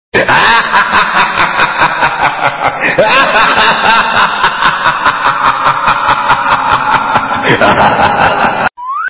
/32kbps) 16kbps (17.7кб) Описание: Смех мужчины ID 24941 Просмотрен 6969 раз Скачан 1688 раз Скопируй ссылку и скачай Fget-ом в течение 1-2 дней!